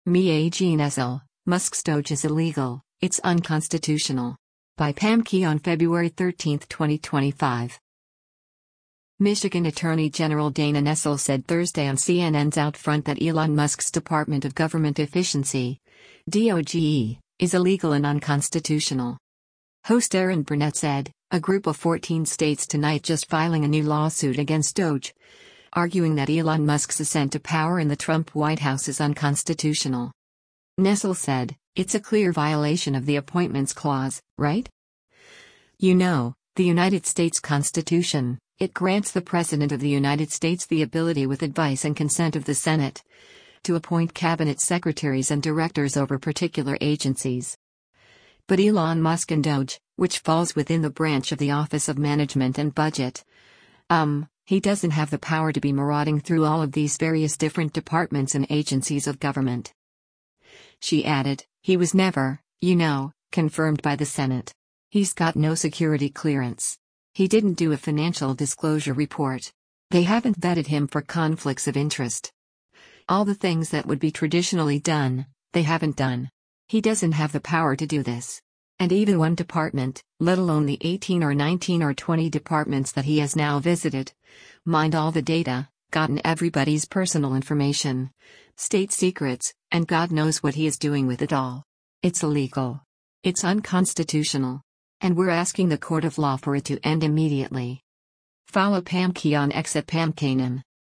Michigan Attorney General Dana Nessel said Thursday on CNN’s “OutFront” that Elon Musk’s Department of Government Efficiency (DOGE) is illegal and unconstitutional.